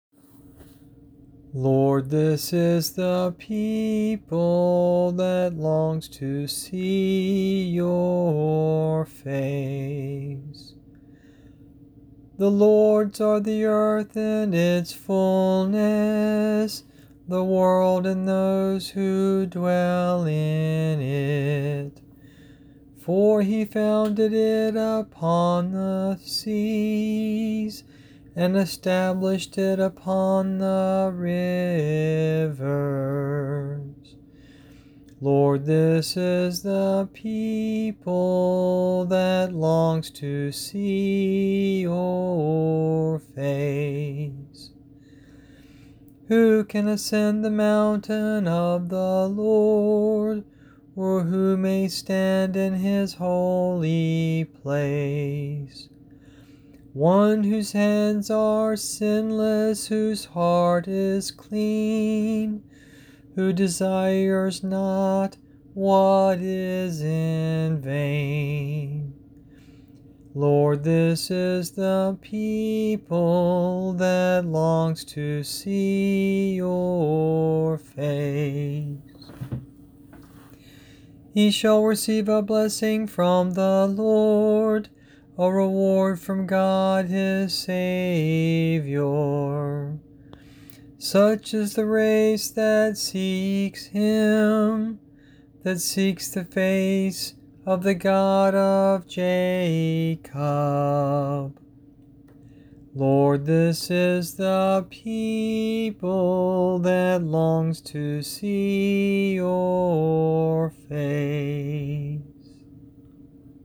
Try lighting your fire with meditative singing.
Psalm 24, Responsorial Form
psalm24.mp3